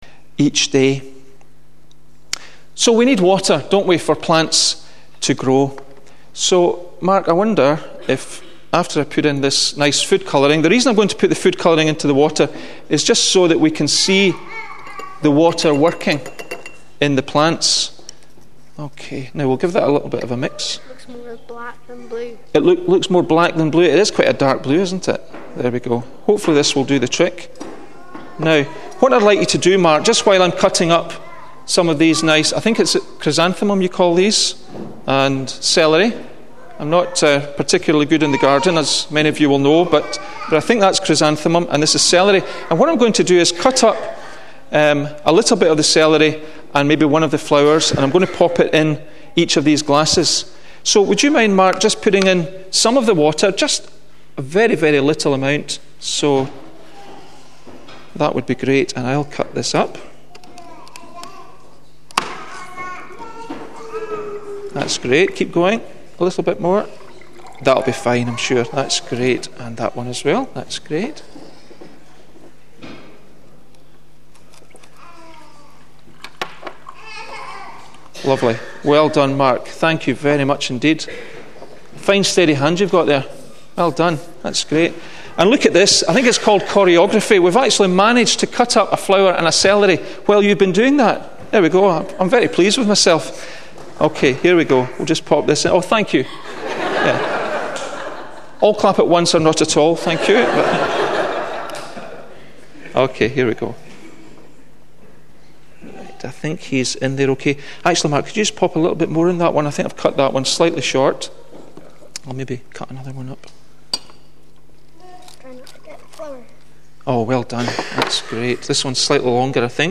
The weekly sermon from Bridge of Don Baptist Church.